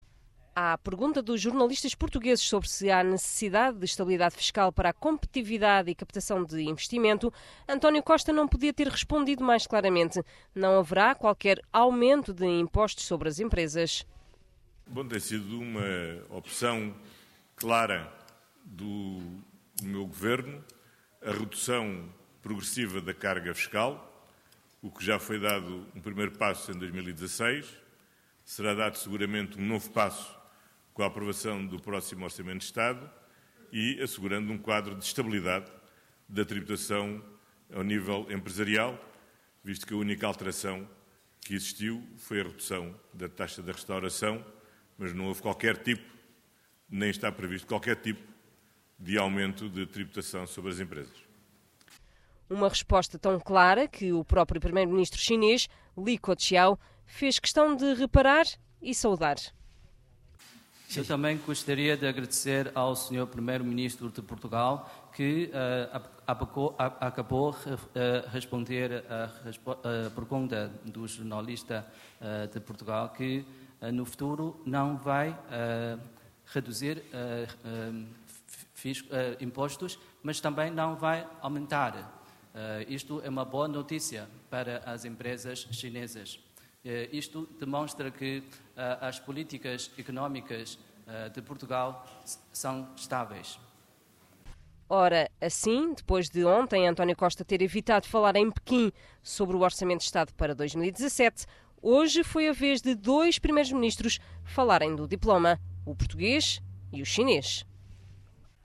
Em Pequim, durante a conferência de imprensa conjunta com primeiro-ministro chinês, questionado sobre a necessidade de estabilidade fiscal para garantir competitividade, o primeiro-ministro disse que a redução fiscal tem sido progressiva e assim vai continuar.